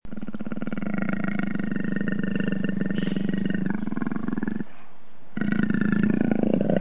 Le léopard | Université populaire de la biosphère
il rugit
leopard.mp3